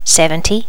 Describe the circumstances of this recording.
Update all number sounds so they are more natural and remove all clicks.